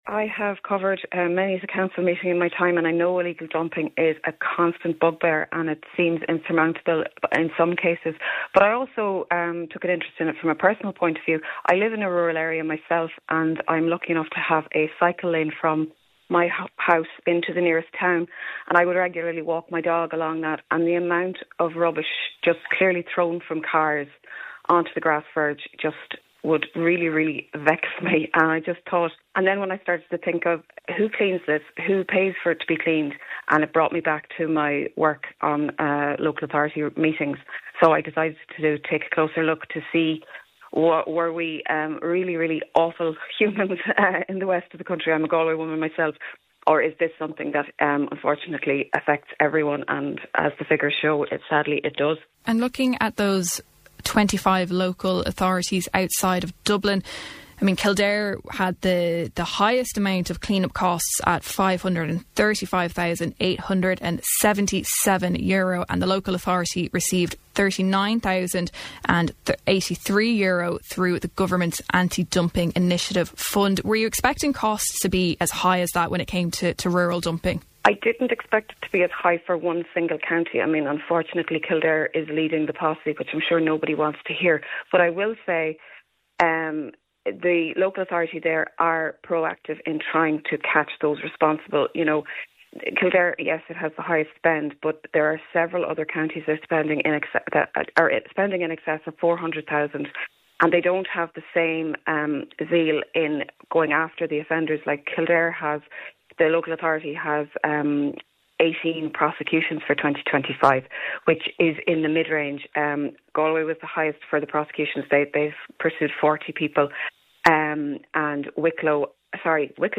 Speaking on Kildare Today